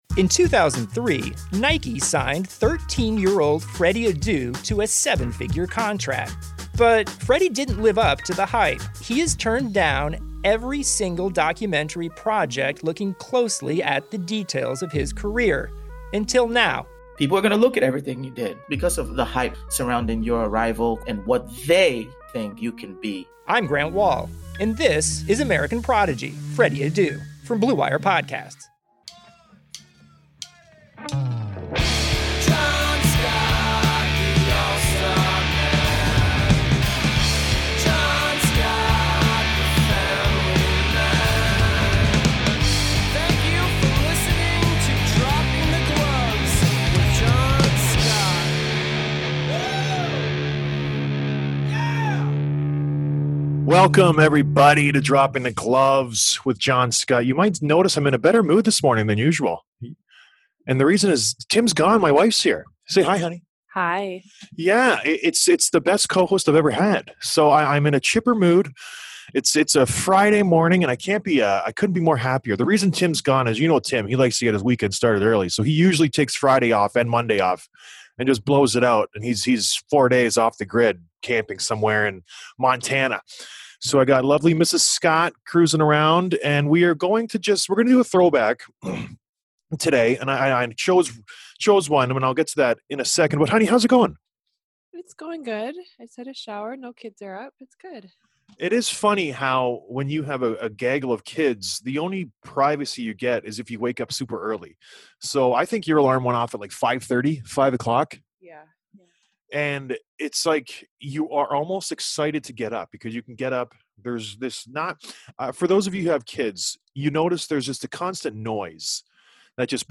Throwback: Rasmus Ristolainen Interview
So, Risto called into the show and told his side of the story!